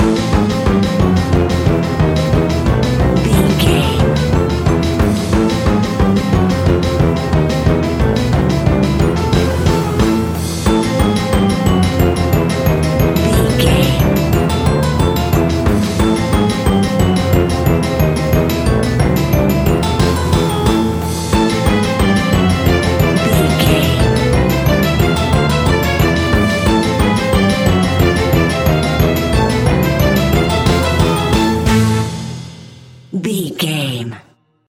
Aeolian/Minor
strings
brass
synthesiser
percussion
piano
spooky
horror music